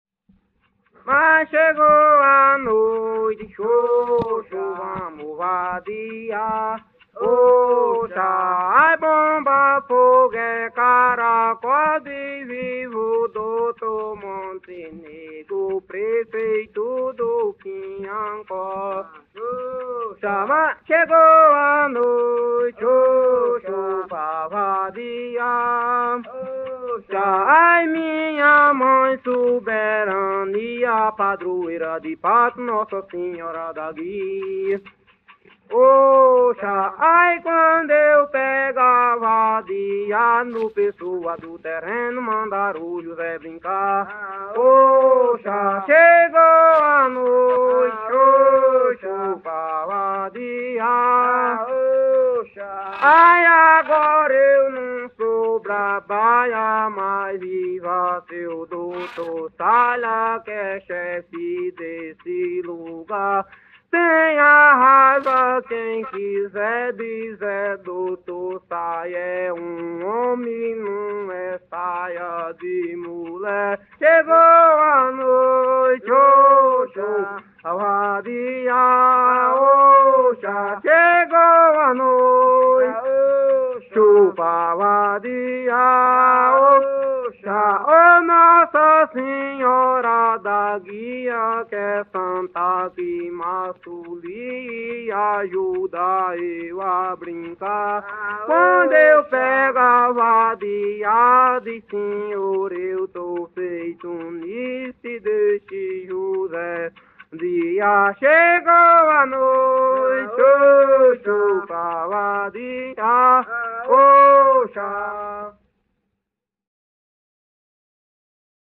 Coco de parelha - ""Ocha""